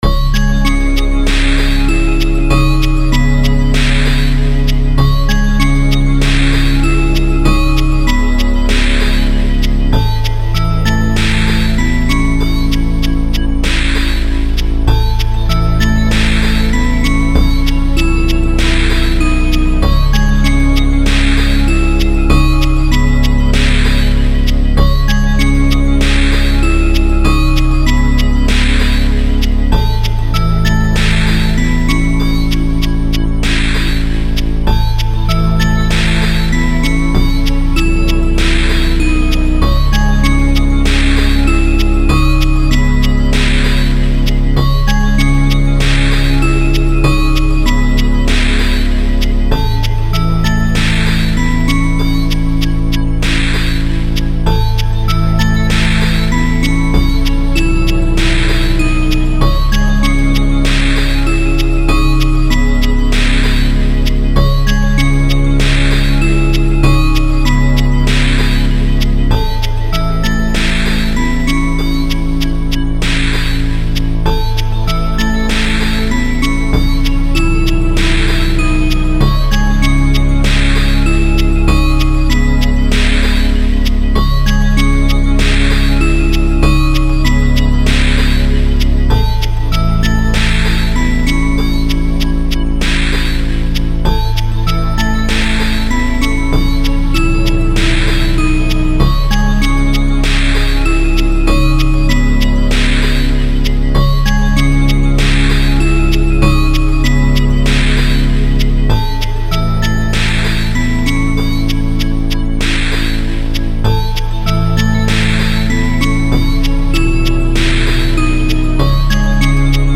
Short looping improv riff.